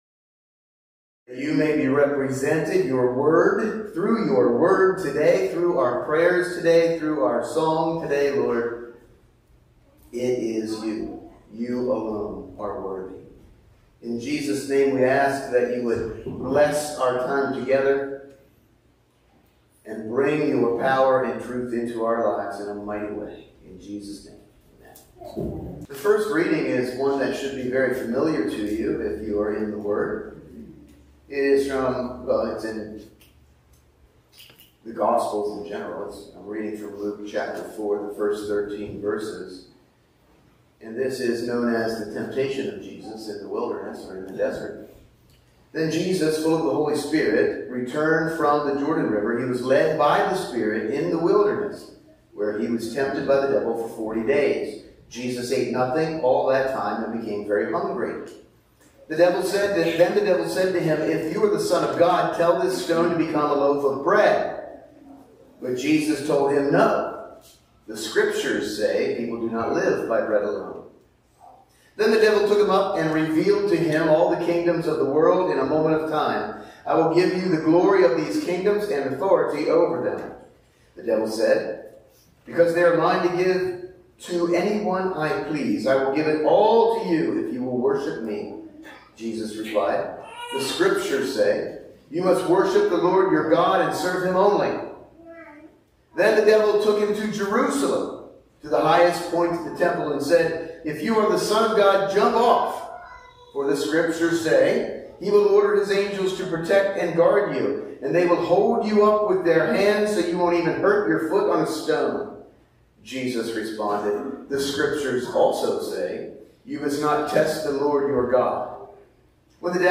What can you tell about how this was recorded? Sunday Morning Service – September 1, 2024 – Churchtown Church of God Luke 4:1-13, Luke 4:31-41; Mark 9:14-29, Luke 8:26-33 Join us for the Sunday morning service at the Churchtown Church of God.